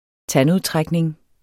Udtale [ ˈtan- ]